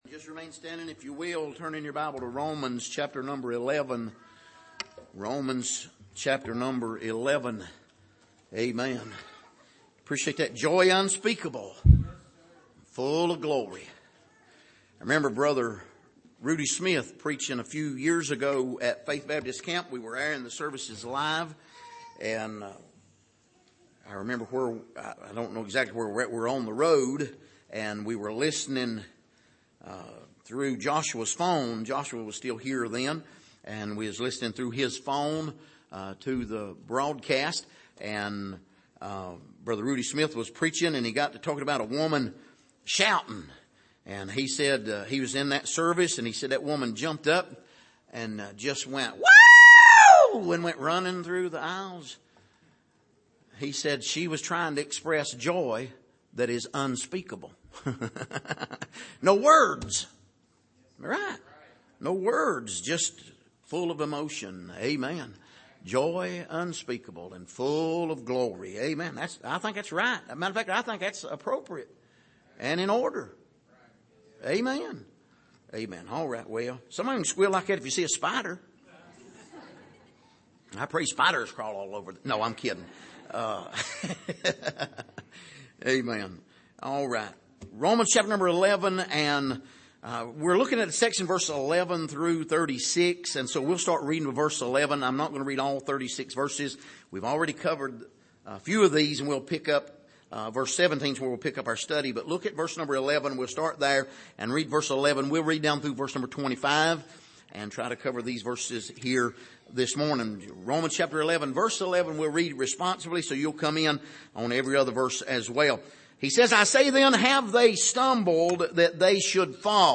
Passage: Romans 11:11-25 Service: Sunday Morning